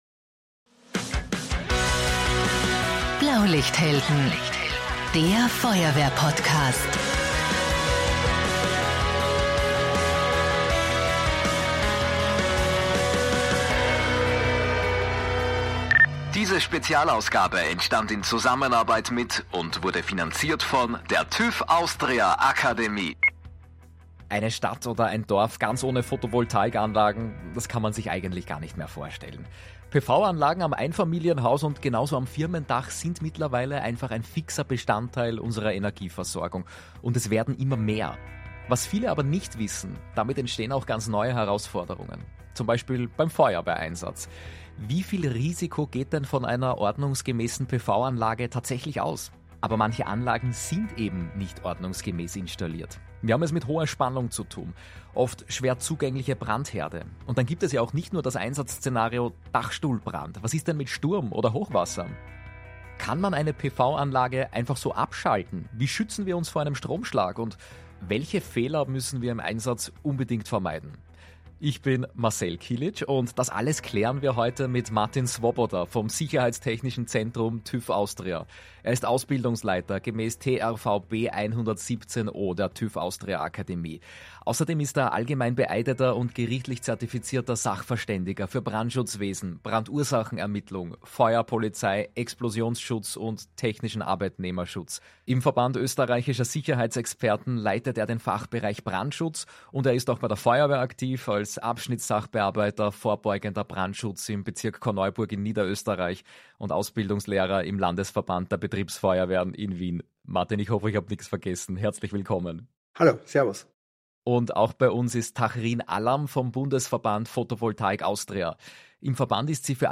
In dieser Folge melden wir uns „live“ von der großen Award-Show im Ö3-Haus am Wiener Küniglberg.